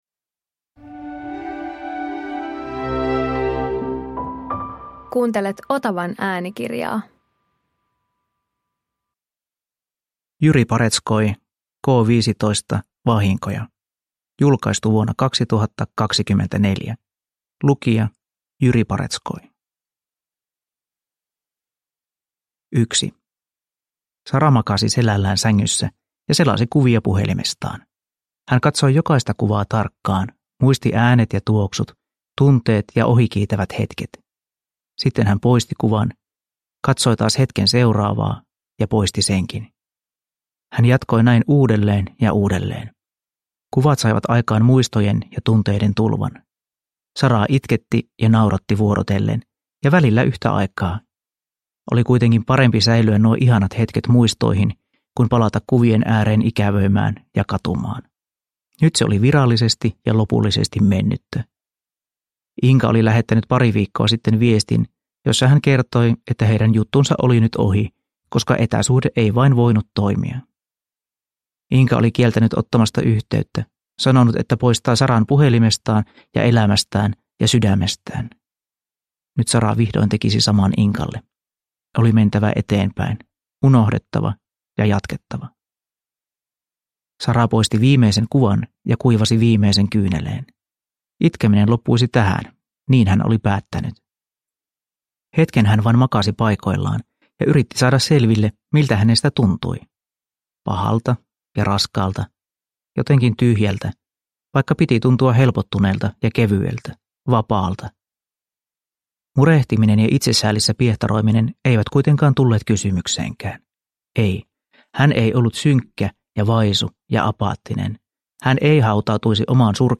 K15 - Vahinkoja – Ljudbok